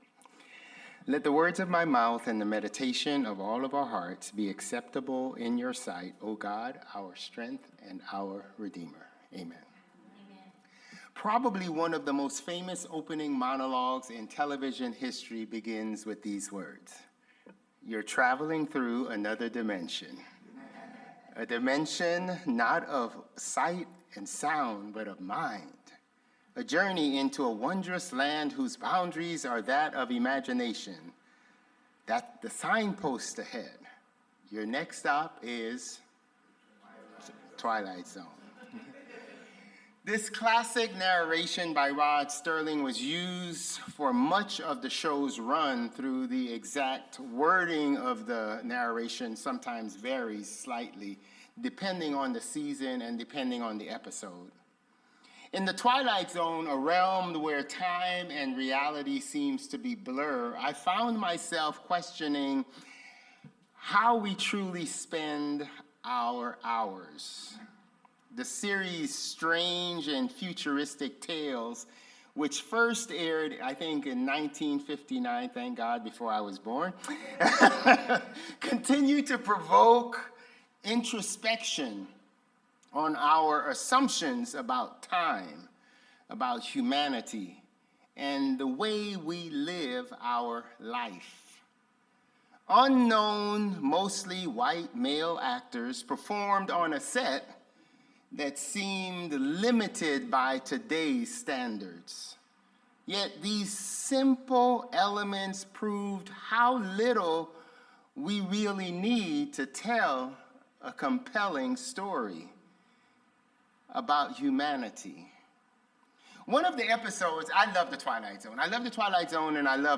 Sermons | Bethel Lutheran Church